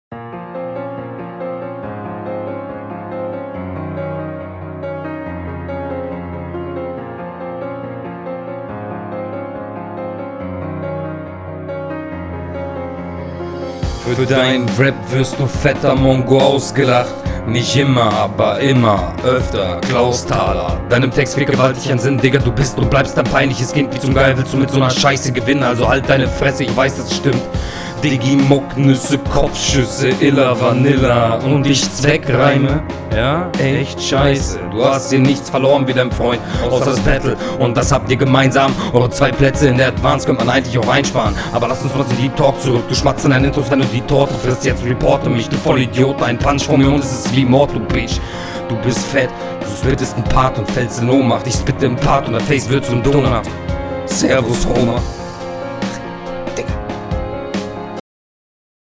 Doubles teils sehr unsauber.